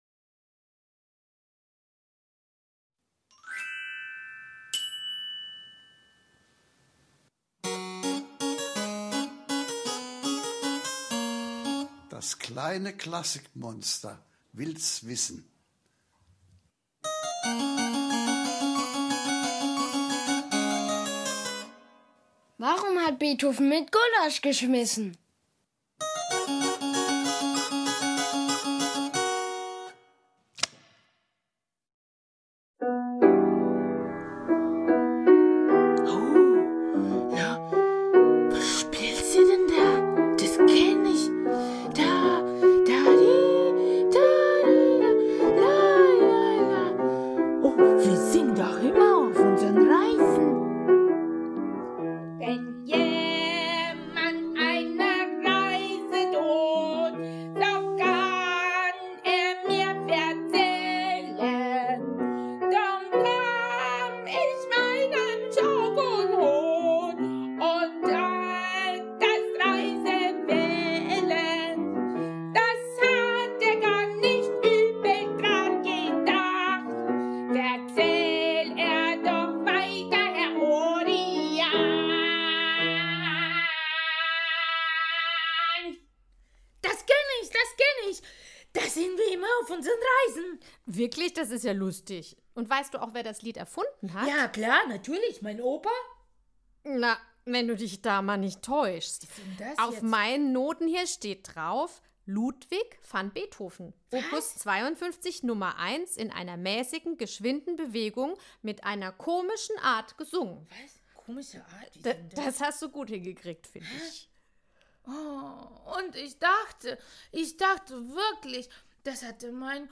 Letzte Episode Beethovens Kindheit in Bonn 26. März 2021 Nächste Episode download Beschreibung Teilen Abonnieren In Folge 2 demonstriert das kleine Monster sein unglaubliches Gesangstalent und erfährt spannendes über Beethovens Kindheit in Bonn. Es kann gar nicht genug bekommen von den 9 Variationen über ein Thema von Dressler, Beethovens erster Komposition. Er veröffentlichte sie mit Hilfe seines Lehrers Johann Gottlob Neefe im Alter von 12 Jahren.